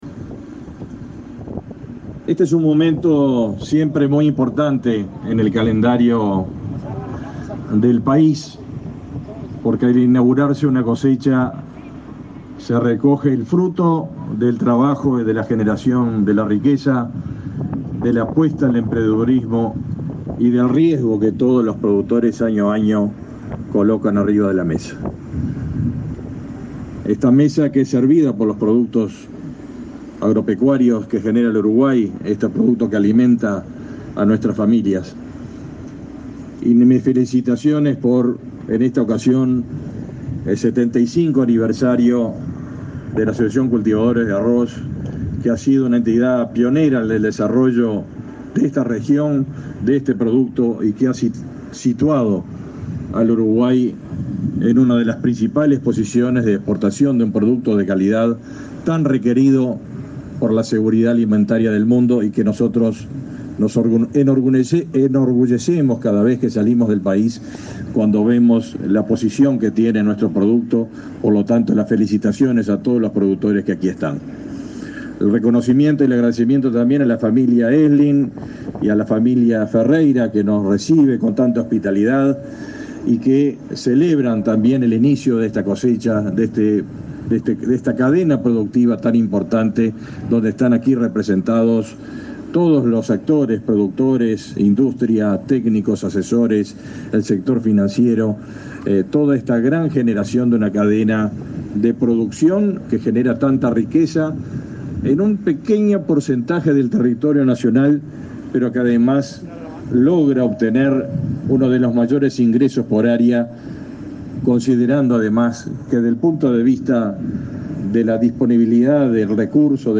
Palabras del ministro de Ganadería, Fernando Mattos
El ministro de Ganadería, Fernando Mattos, habló este jueves 18 en Cerro Largo, en la inauguración de la cosecha de arroz, que fue encabezada por el